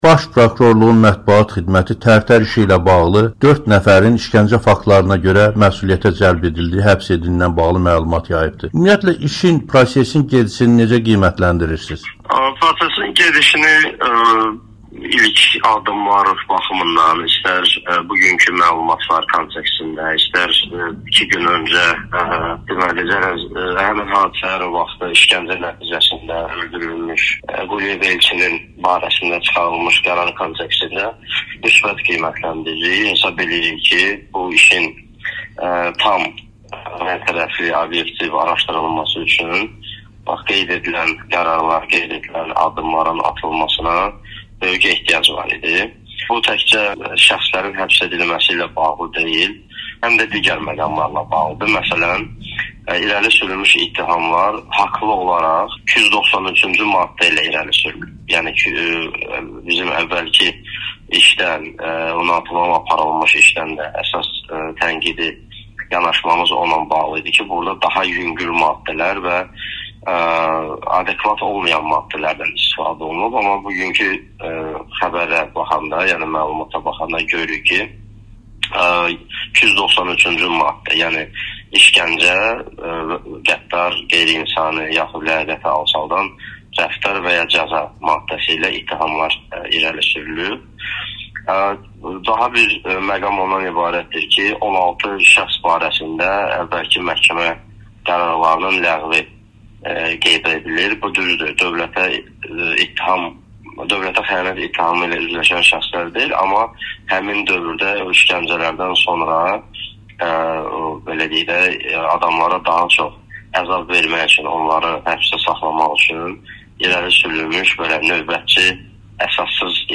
Hüquq müdafiəçisi: Prokurorluq hərtərəfli araşdırma aparır